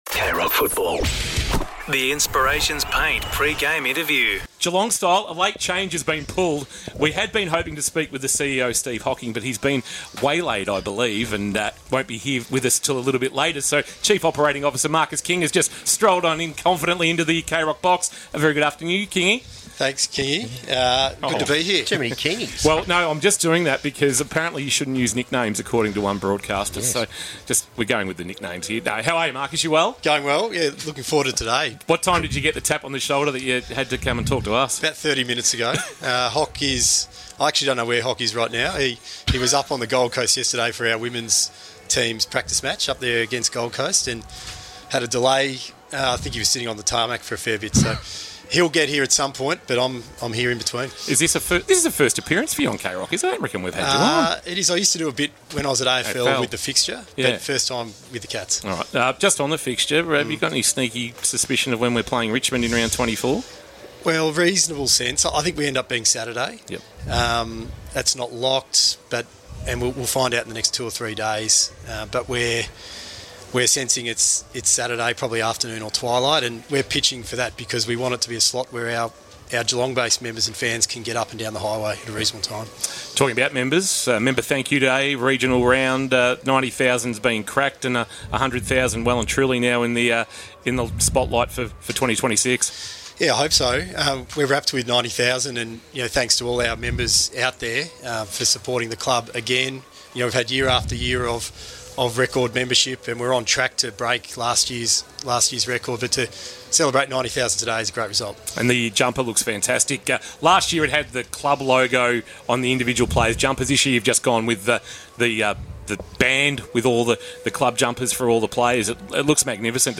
2025 - AFL - Round 21 - Geelong vs. Port Adelaide: Pre-match interview